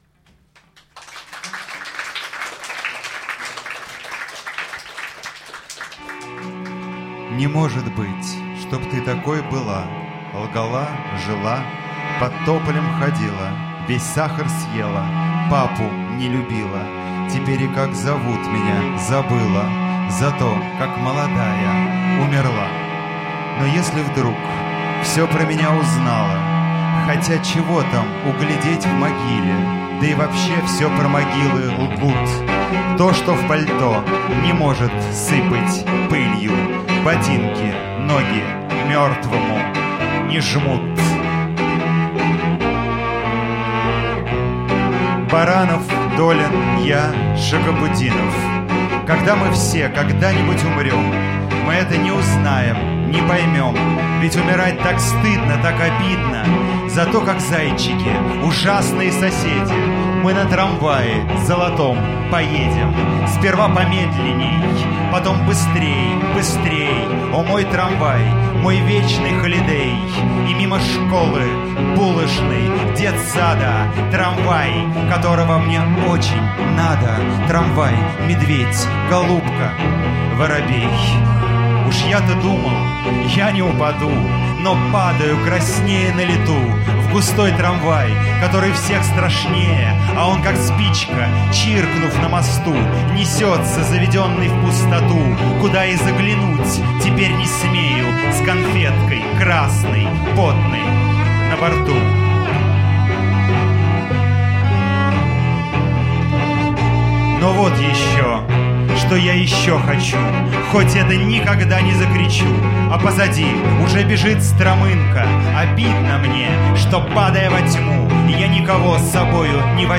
Концерт в «Практике», 2009 год.